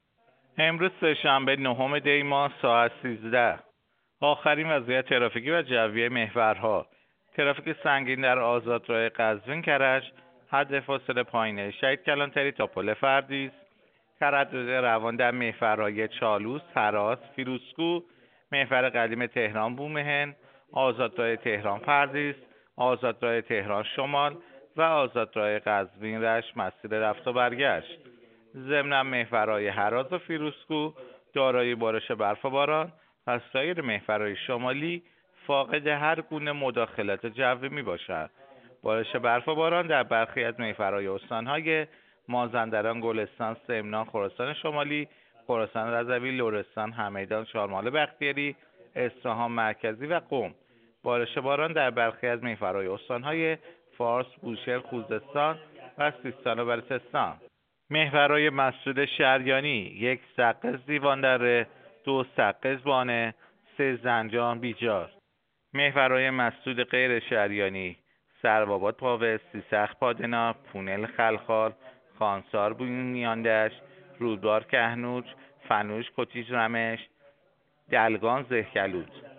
گزارش رادیو اینترنتی از آخرین وضعیت ترافیکی جاده‌ها ساعت ۱۳ نهم دی؛